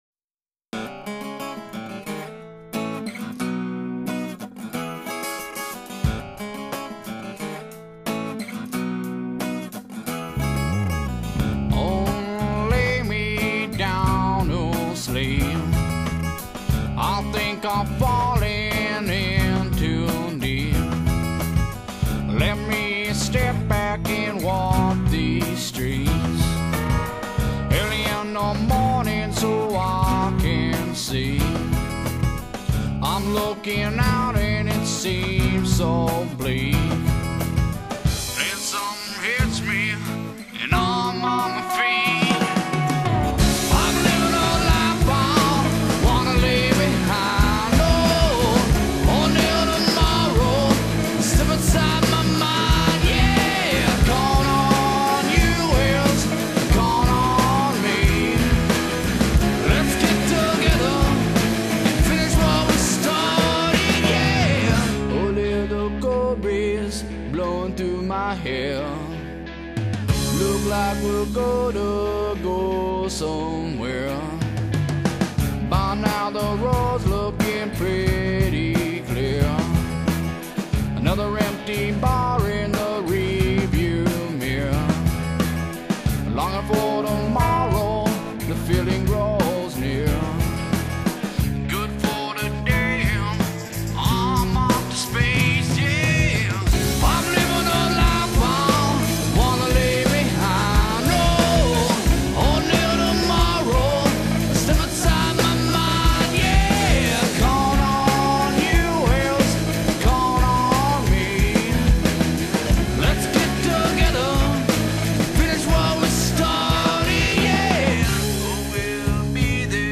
guitar
His voice resounds with athority far beyond his years.